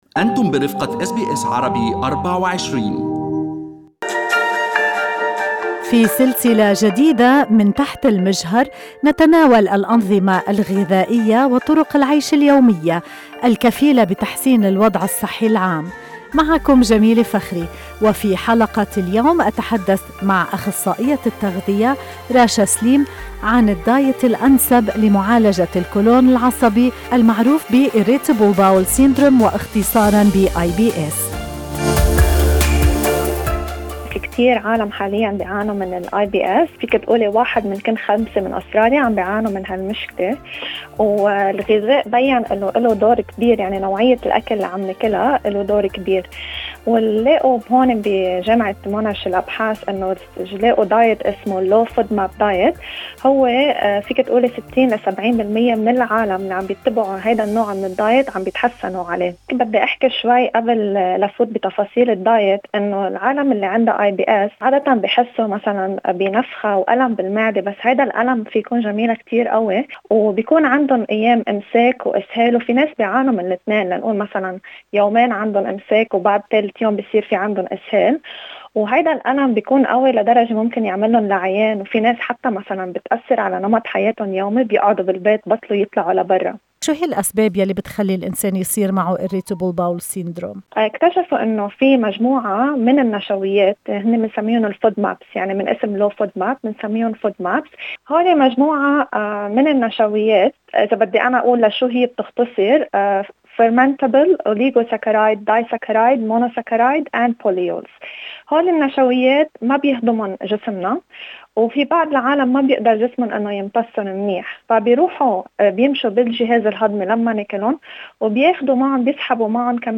إنتفاخ في المعدة، آلام في البطن، غازات زائدة، إسهال أو إمساك معا أوالإثنان بالتناوب، ان كنت تشكي من إحدى هذه العوارض، تعرف على الأسباب وطرق الوقاية والعلاج في هذا اللقاء.